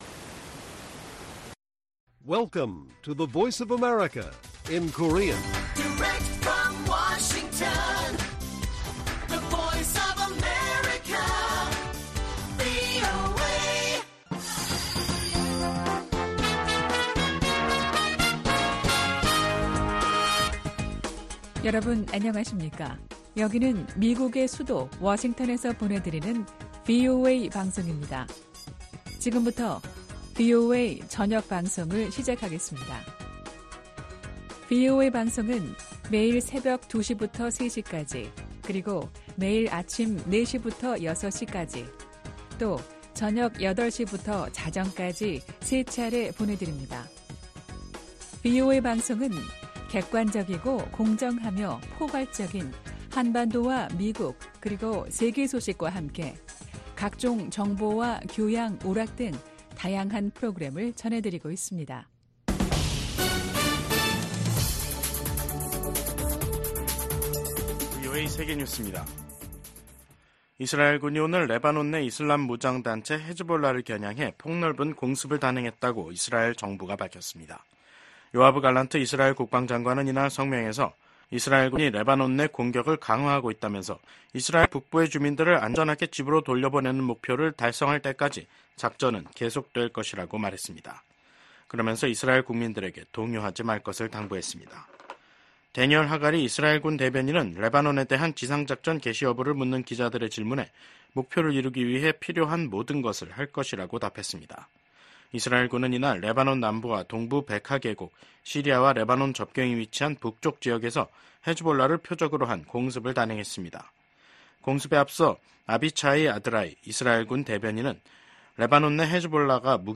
VOA 한국어 간판 뉴스 프로그램 '뉴스 투데이', 2024년 9월 23일 1부 방송입니다. 미국과 일본, 호주, 인도 정상들이 북한의 미사일 발사와 핵무기 추구를 규탄했습니다. 미국 정부가 전쟁포로 실종자 인식의 날을 맞아 미군 참전용사를 반드시 가족의 품으로 돌려보낼 것이라고 강조했습니다. 유엔 북한인권특별보고관이 주민에 대한 통제 강화 등 북한의 인권 실태가 더욱 열악해지고 있다는 평가를 냈습니다.